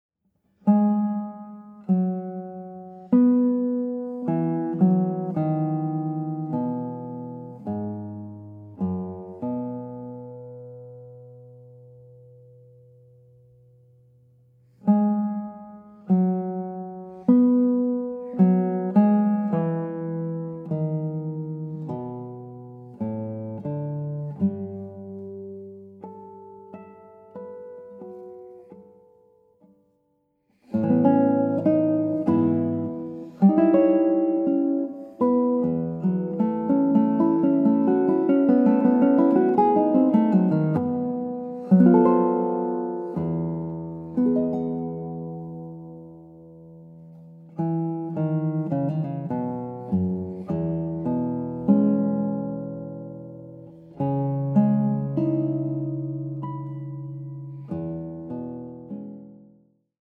Images become sound – five world premieres for guitar